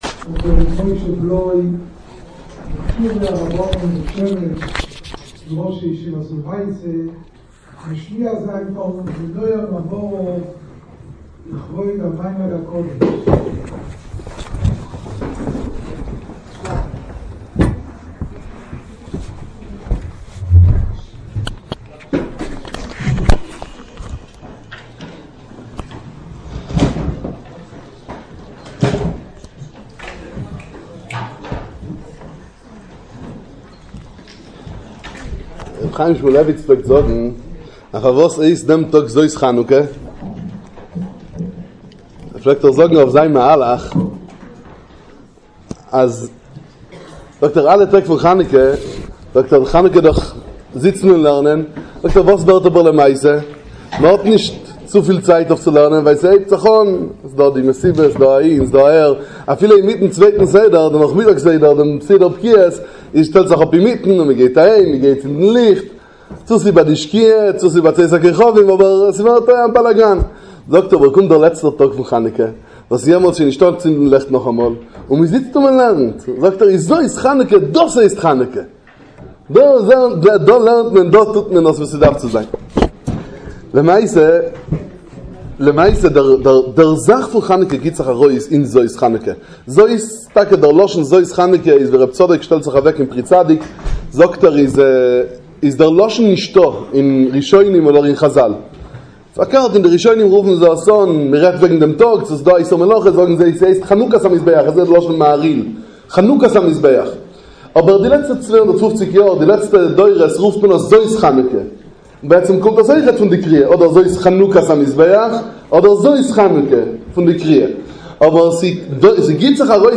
מסיבת חנוכה וסיום מסכתות